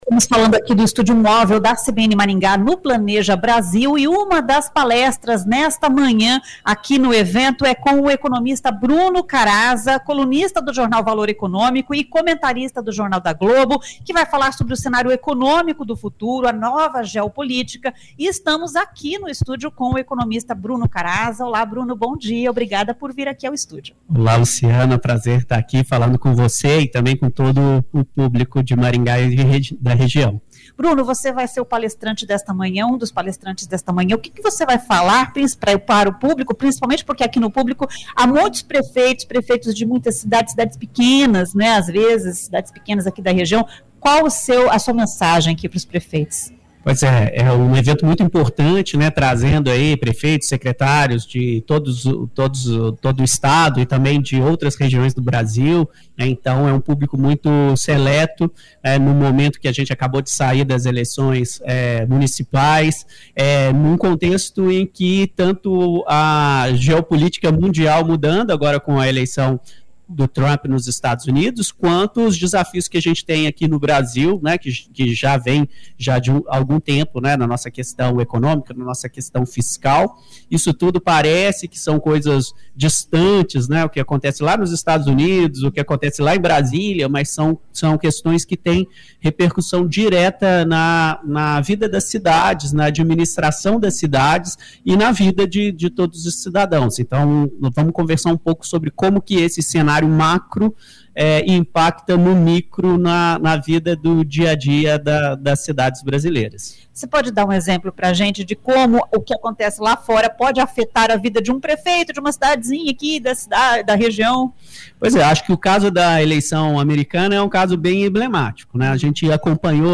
A entrevista foi realizada no estúdio móvel CBN instalado no local do evento.